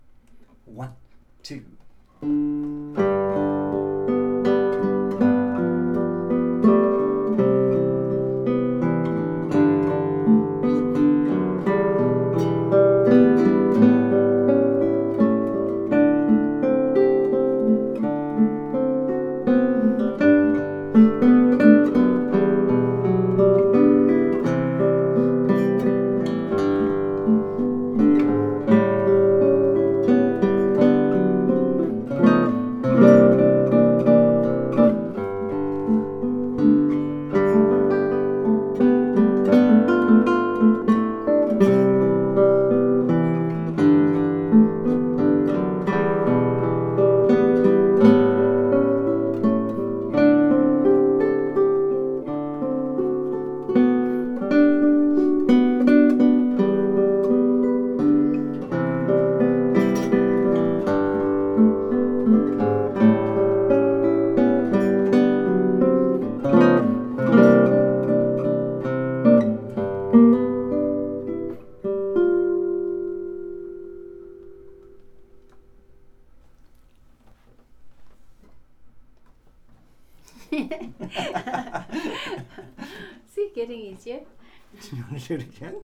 Amazing Grace | Melody and chords